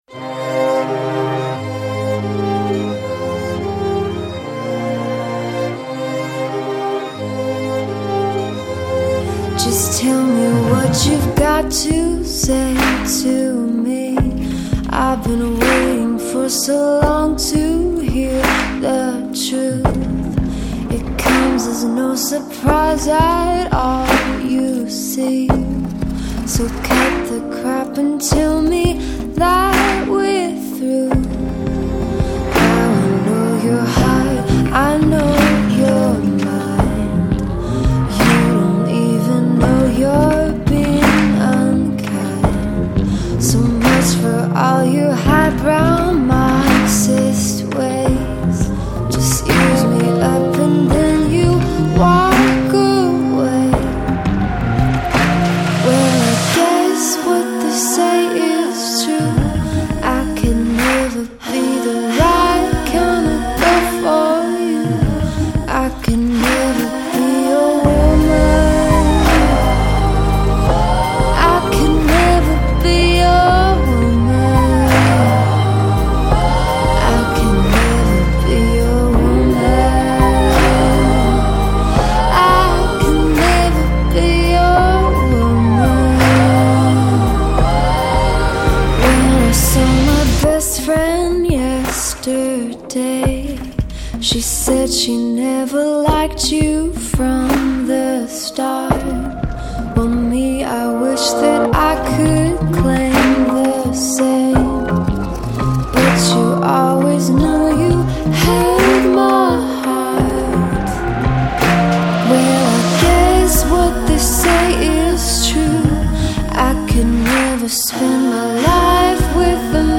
indie electropop
a more cinematic and raw approach